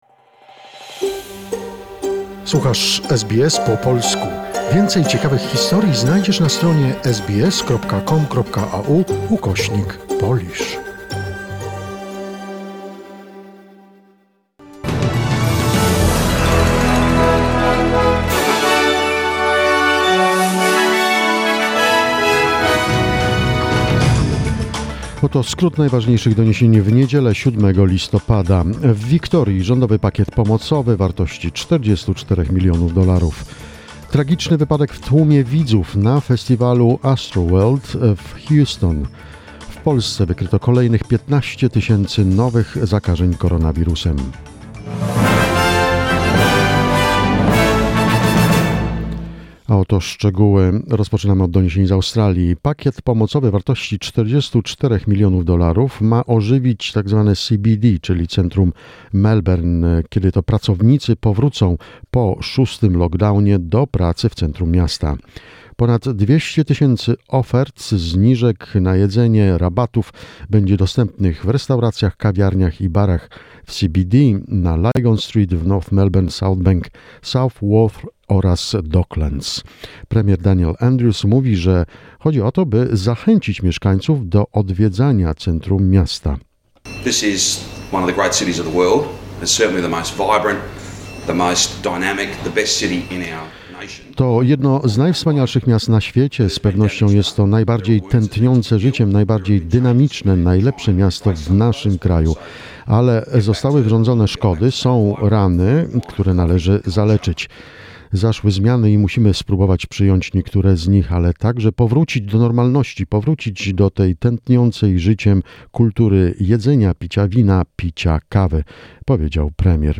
SBS News in Polish, 7 November 2021